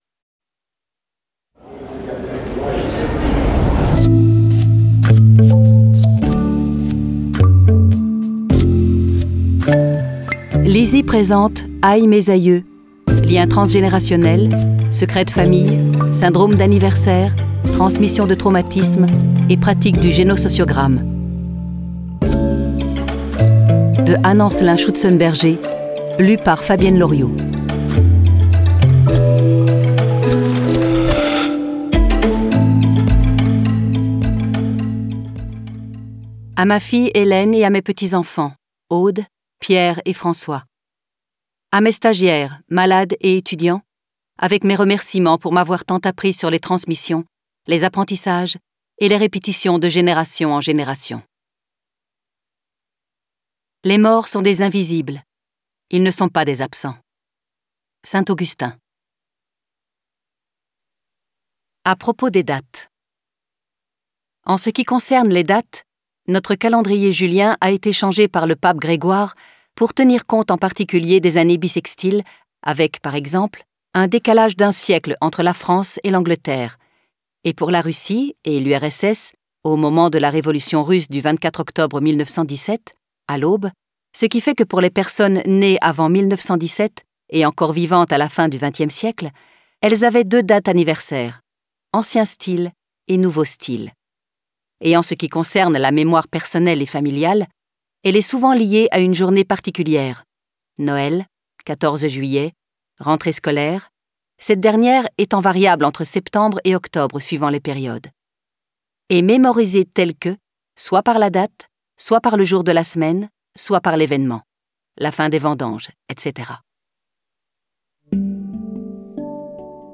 je découvre un extrait - Aïe, mes aïeux ! de Anne ANCELIN SCHÜTZENBERGER